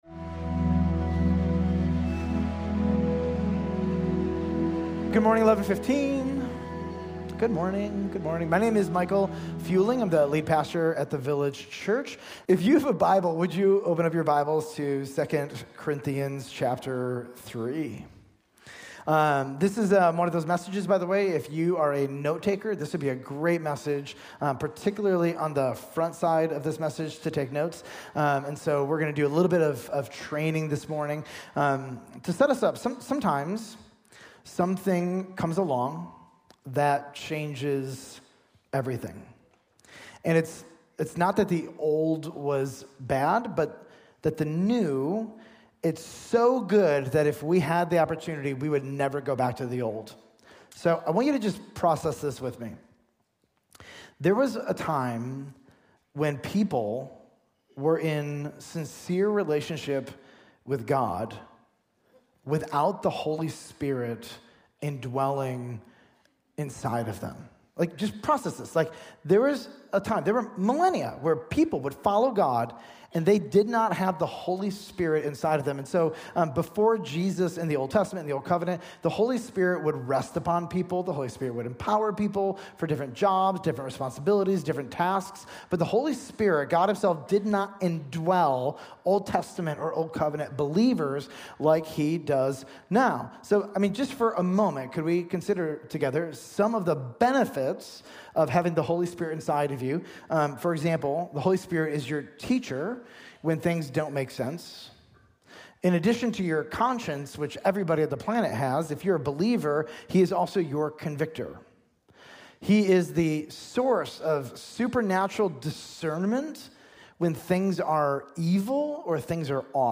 Village Church of Bartlett: Sermons 2 Corinthians - Gospel Ministry Pt. 2: New Covenant Ministry